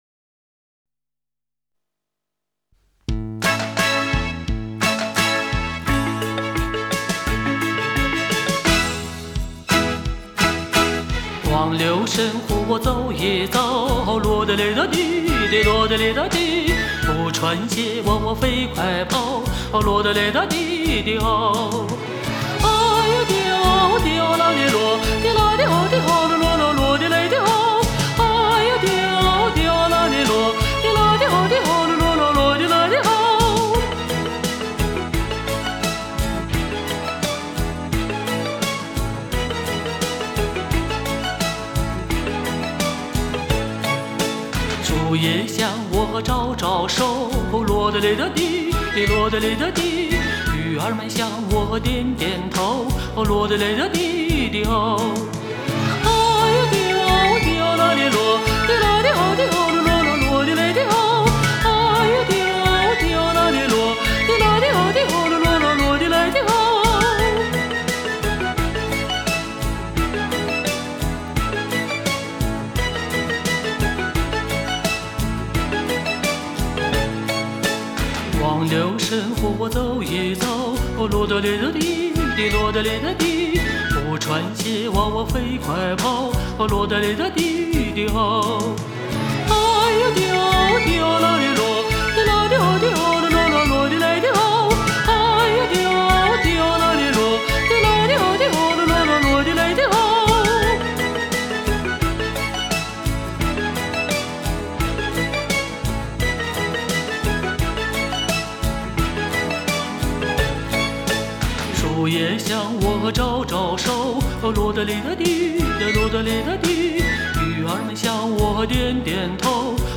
她将美声唱法融汇贯通